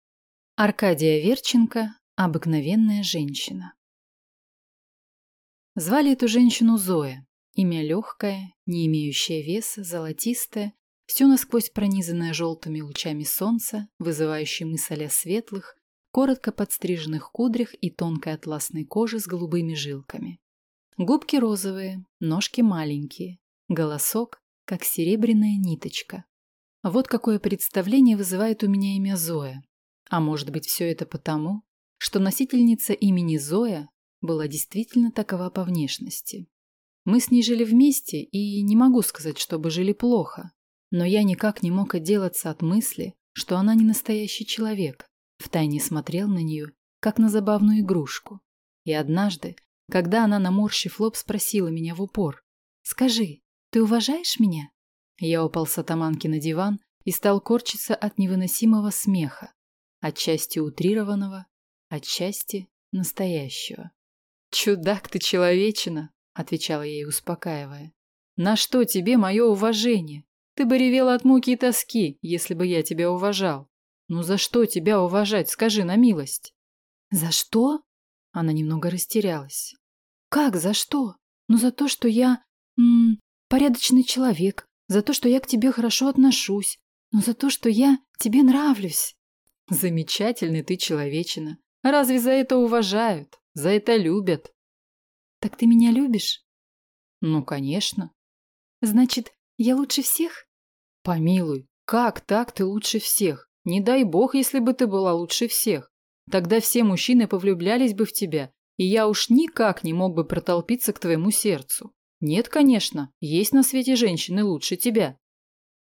Аудиокнига Обыкновенная женщина | Библиотека аудиокниг